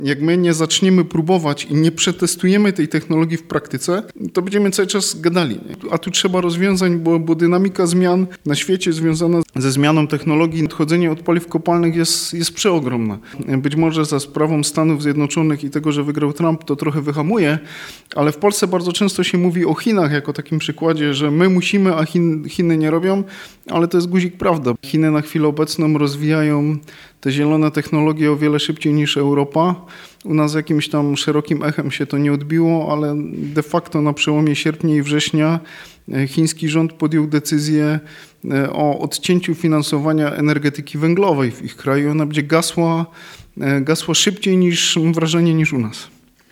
W wypowiedzi dla Twojego Radia podkreśla, że konieczne jest dążenie do zero-emisyjności gospodarki i odchodzenie od źródeł kopalnych.